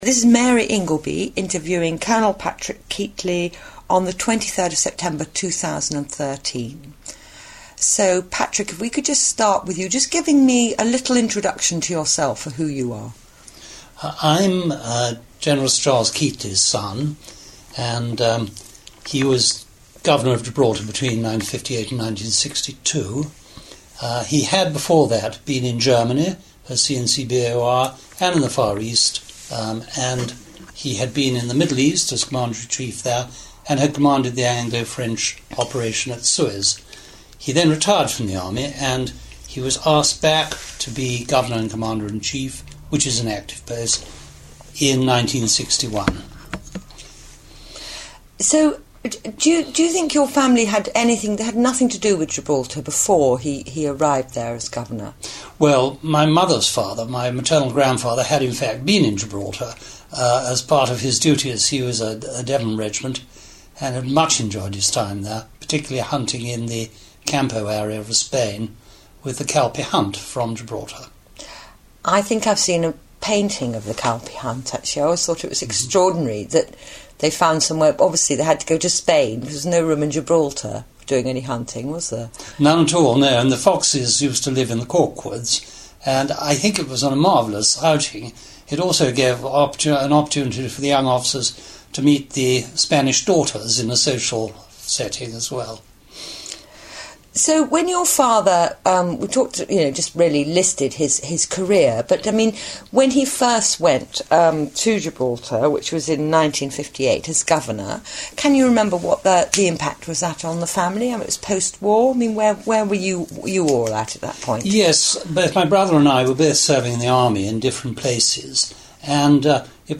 Friends of Gibraltar Oral History